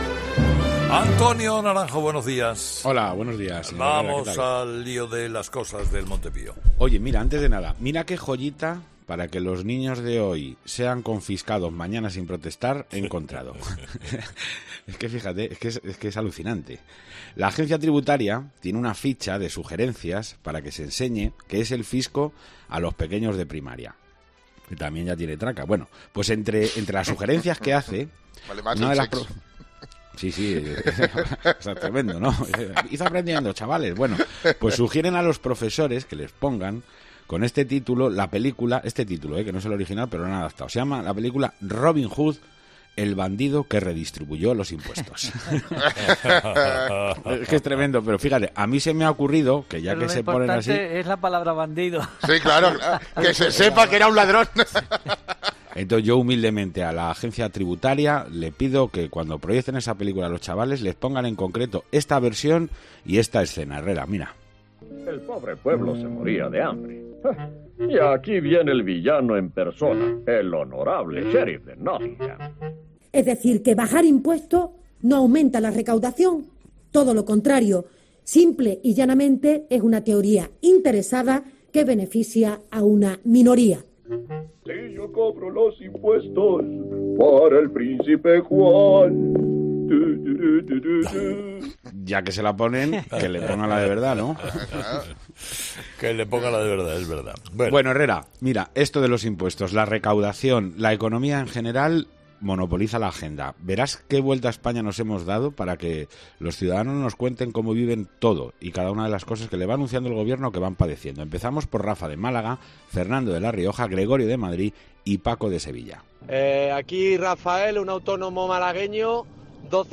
La presión fiscal en España ha sido objeto de debate entre los oyentes que han llamado a 'Herrera en COPE'